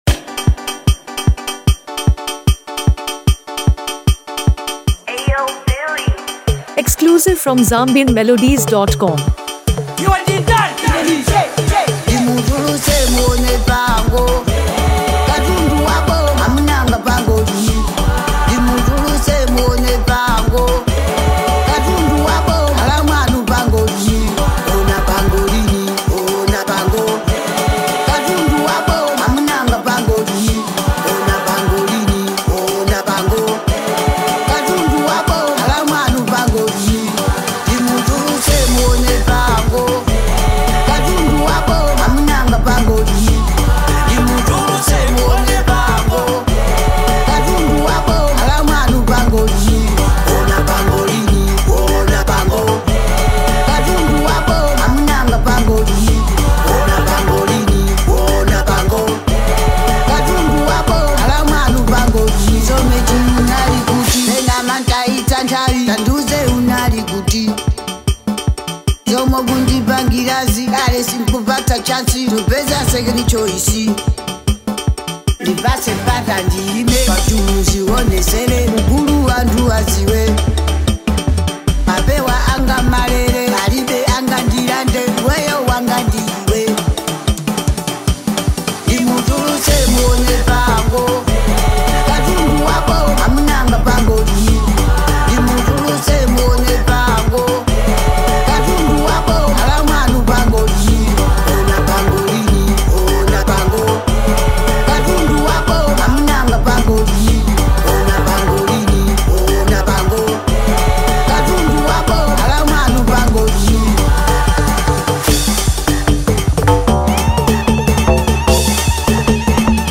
modern Malawian Afrobeat